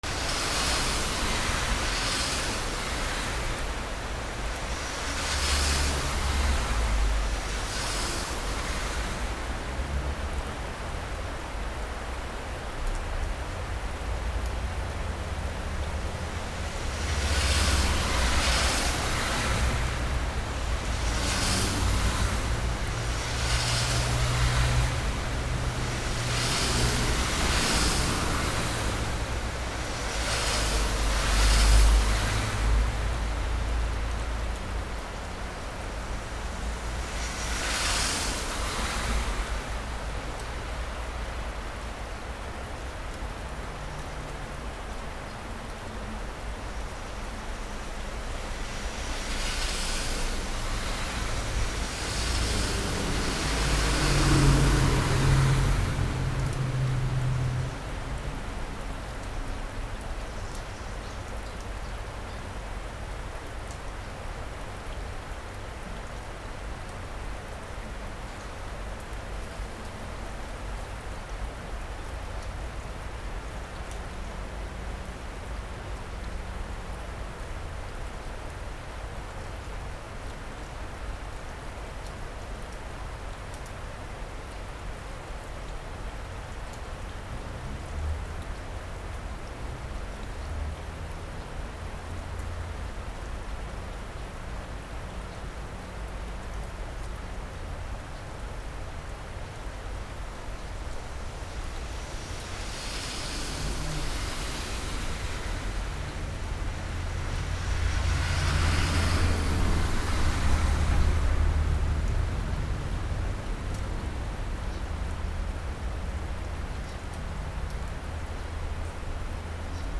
/ A｜環境音(天候) / A-30 ｜雨 道路
雨 道路
ゴー ビシャー